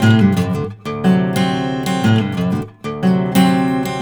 Index of /90_sSampleCDs/ILIO - Fretworks - Blues Guitar Samples/Partition G/120BARI RIFF